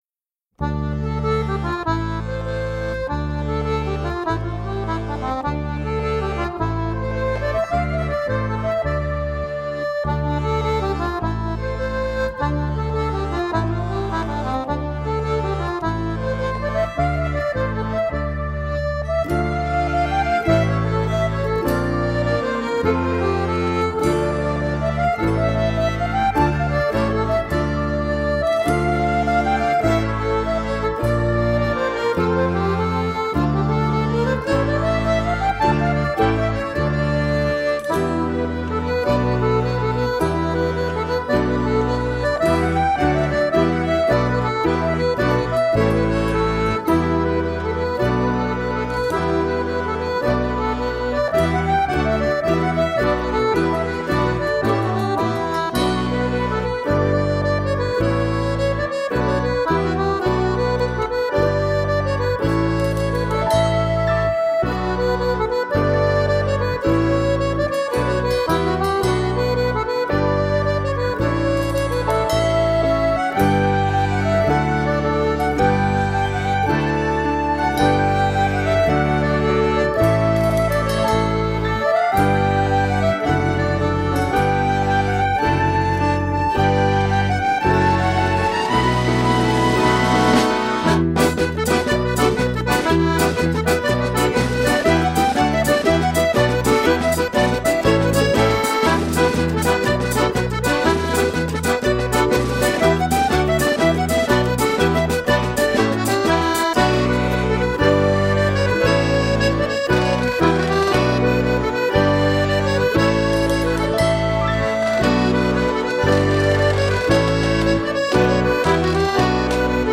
Genre: World Pop.